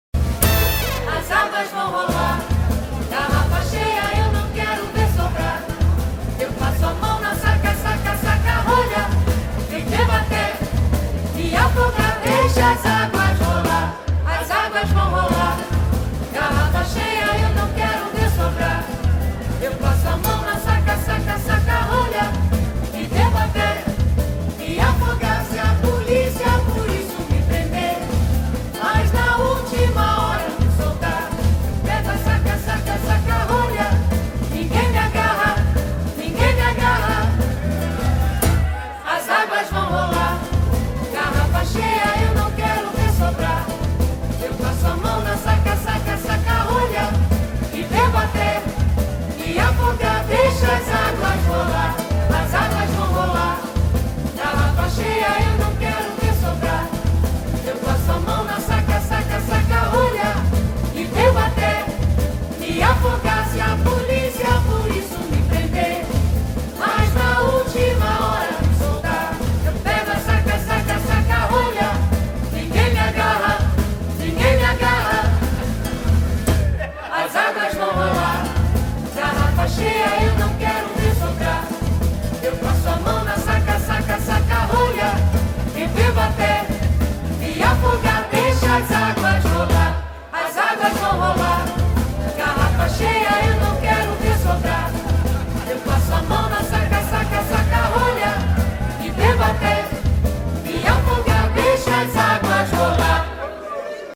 Carpeta: Brasilera mp3